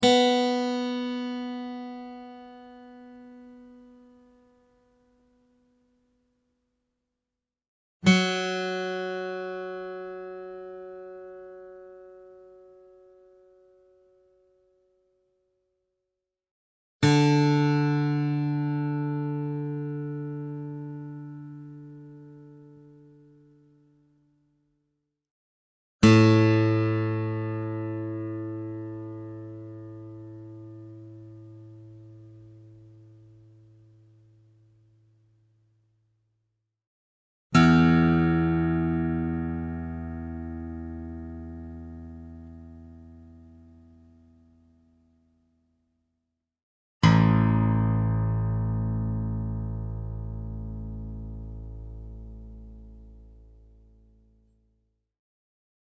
Real acoustic guitar sounds in Drop A Tuning
Guitar Tuning Sounds